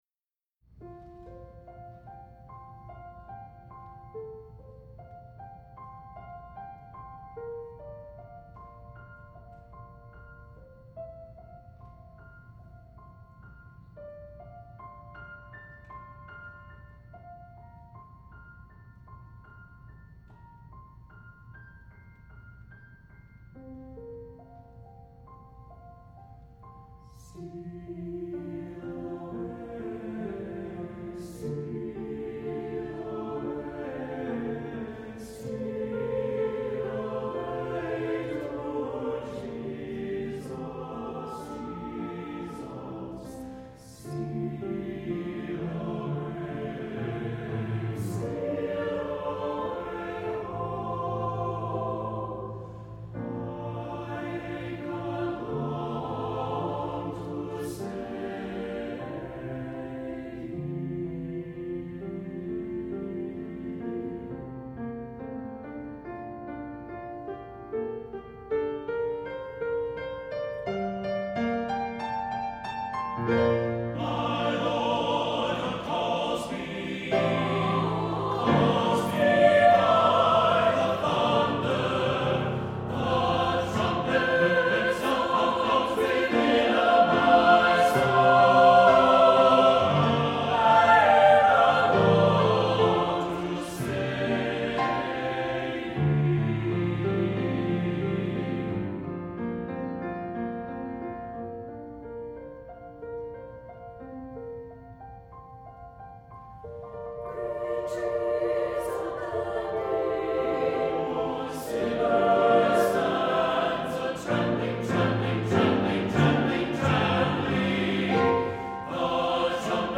for SATB Chorus and Piano (2014)
SATB and piano version